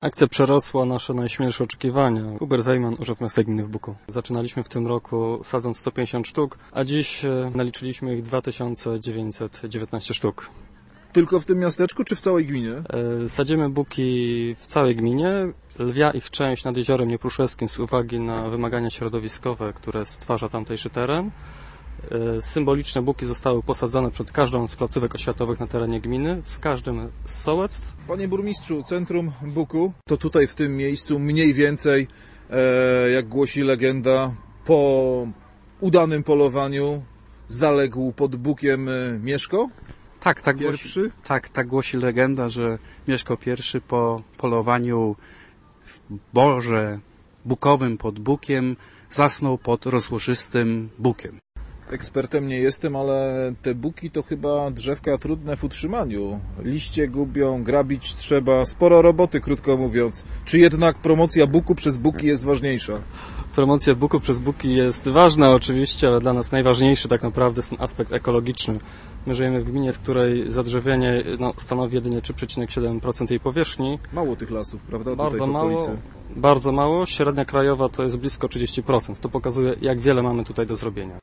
O akcję sadzenia buków w podpoznańskim Buku. Nasz reporter rozmawiał z "dowodzącym" tym projektem burmistrzem Stanisławem Filipiakiem.